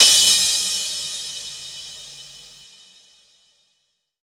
• Big Drum Crash Sample F Key 06.wav
Royality free crash cymbal tuned to the F note. Loudest frequency: 5390Hz
big-drum-crash-sample-f-key-06-Db9.wav